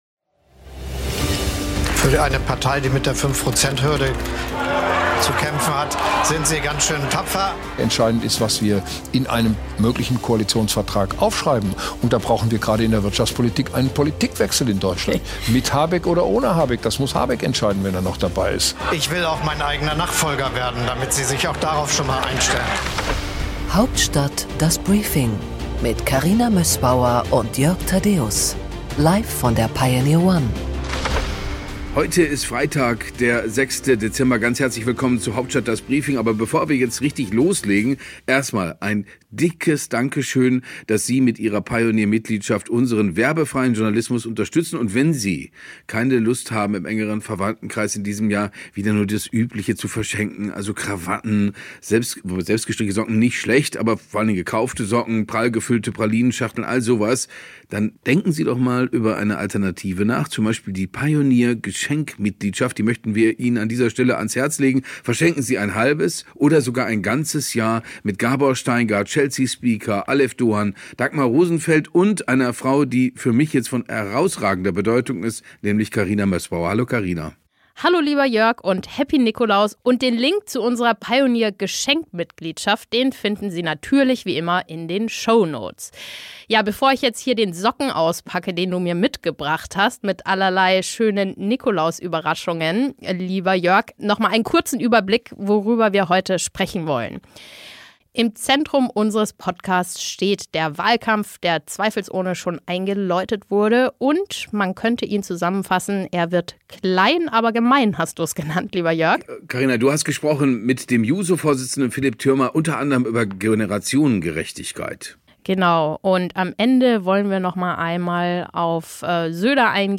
Im Interview der Woche